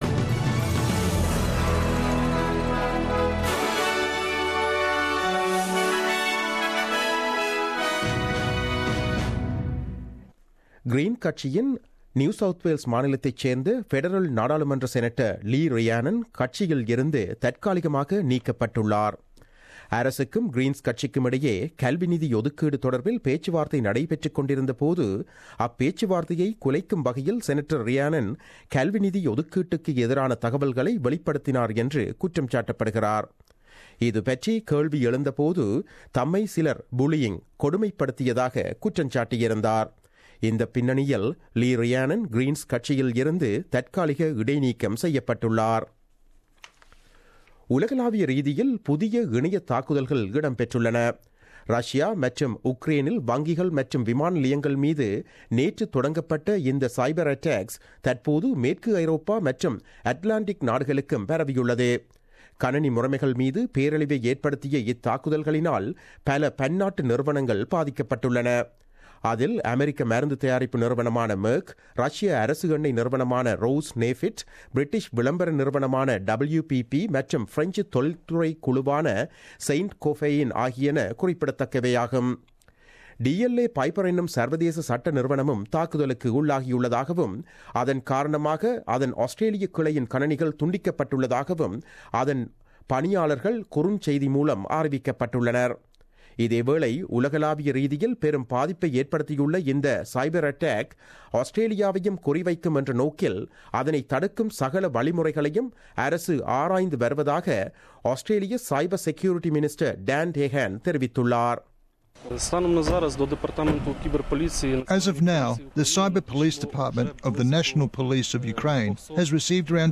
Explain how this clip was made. The news bulletin broadcasted on 28 June 2017 at 8pm.